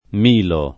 Milo [ m & lo ] → Melo